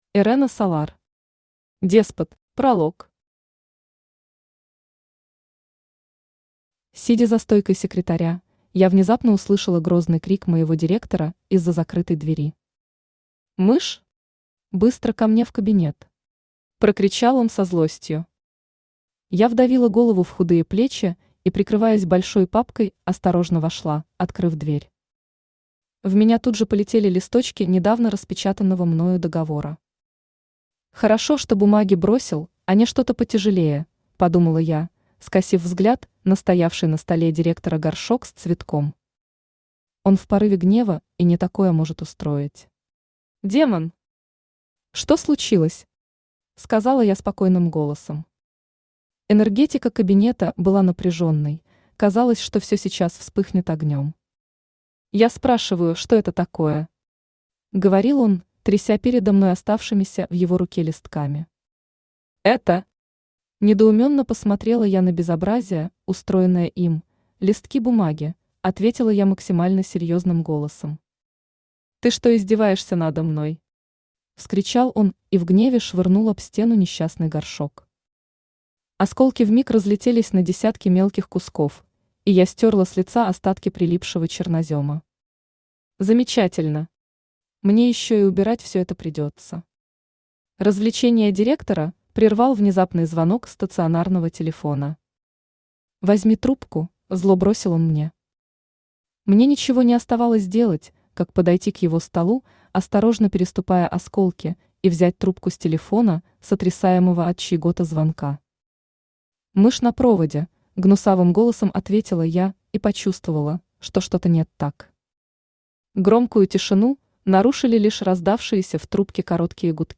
Аудиокнига Деспот | Библиотека аудиокниг
Aудиокнига Деспот Автор Ирэна Солар Читает аудиокнигу Авточтец ЛитРес.